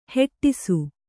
♪ heṭṭisu